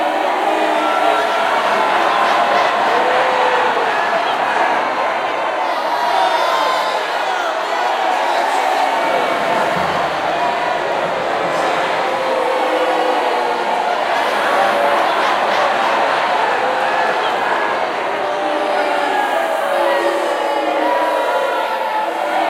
teenageCrowdJeerLoop.ogg